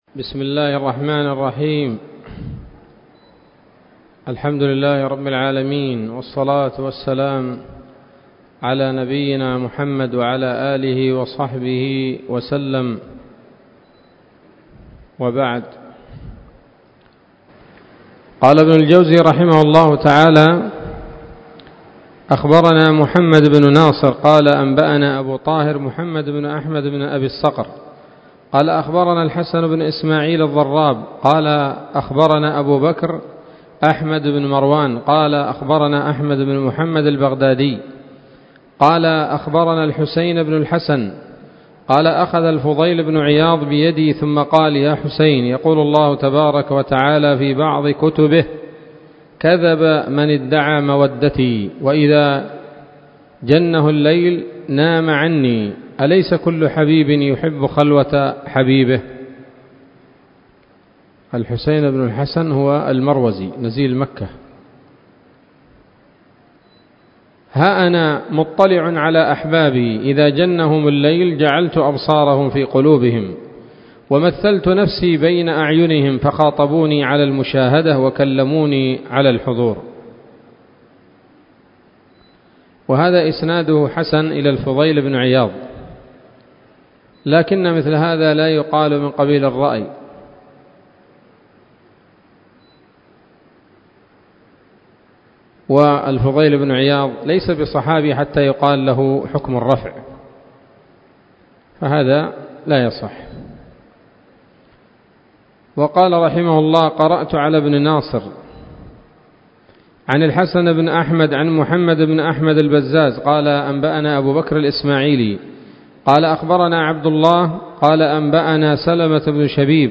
الدرس الخامس من كتاب "قيام الليل" لابن الجوزي رحمه الله تعالى